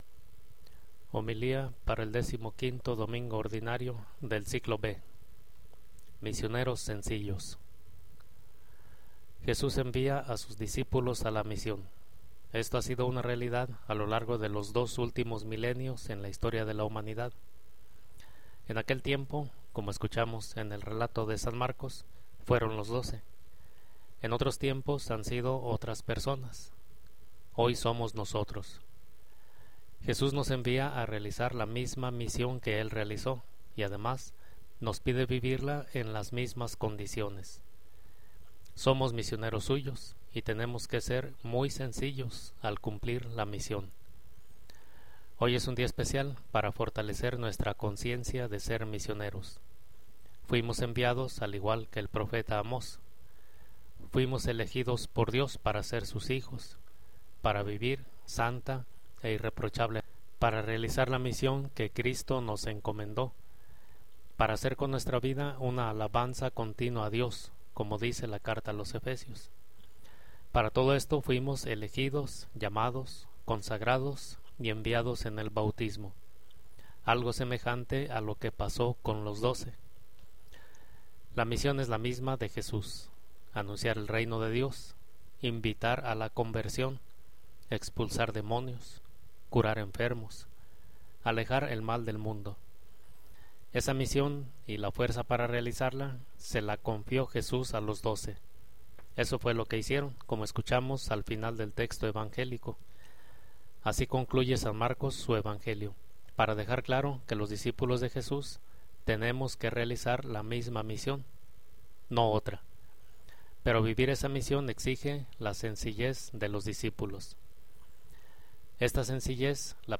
Homilía del 15º domingo ordinario 2012